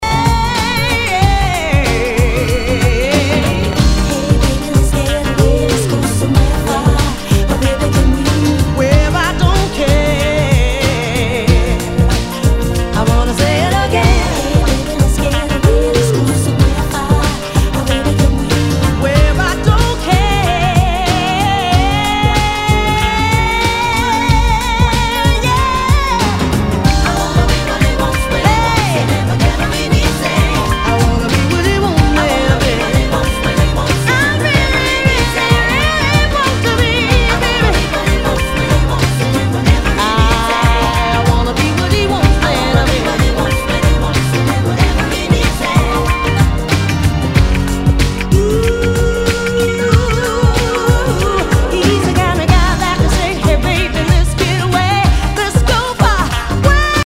Nu- Jazz/BREAK BEATS